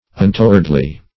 Untowardly \Un*to"ward*ly\, a.